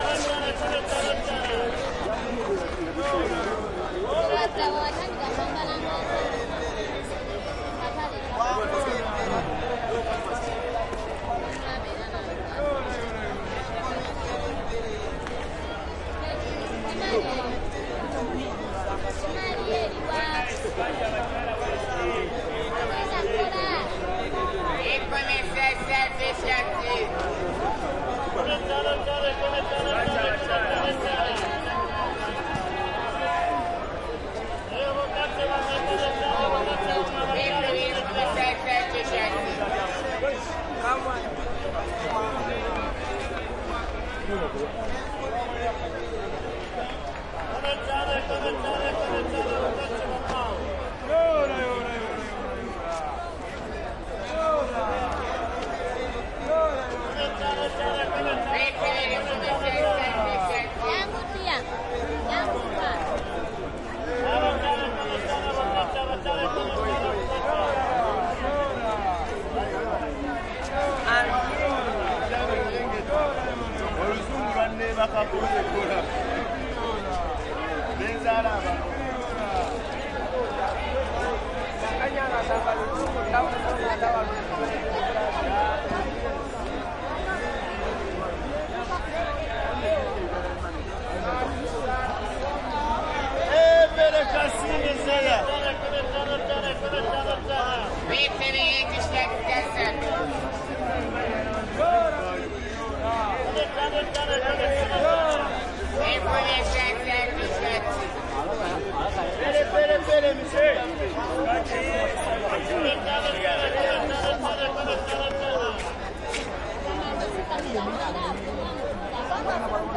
乌干达 " 市场在大的开放性街道上非常繁忙，二手服装中等活跃的人群接近，附近的小贩在洛甘丹喊叫
描述：市场分机忙着大开街第二手服装媒体活跃人群关闭和附近的供应商呼喊Logandan1 +遥远的巴西交通坎帕拉，乌干达，非洲2016.wav
Tag: 市场 服装 供应商 媒体 密切 活跃 乌干达 街道 第二 非洲 人群